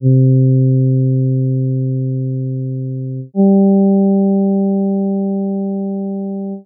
Both voices move in whole notes. The second is an octave below the first.
voice 2: C G =